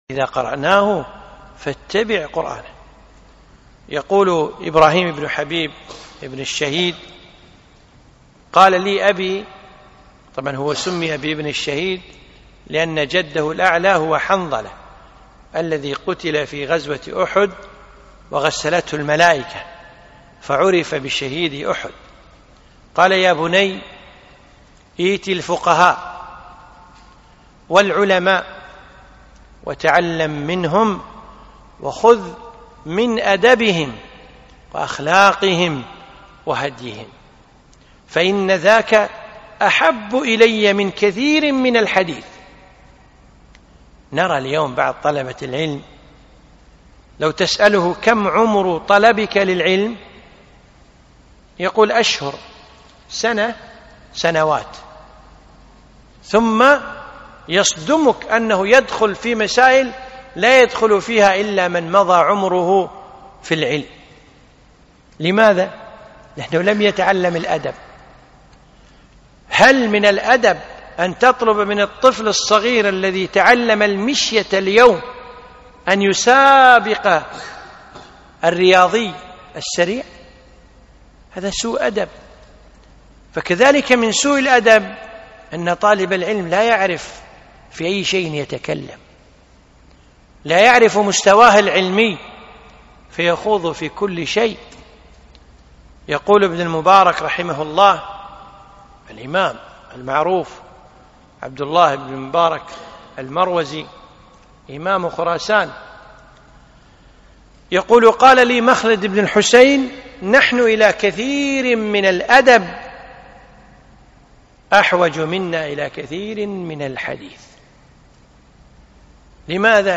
محاضرة - مختصر جامع في آداب طالب العلم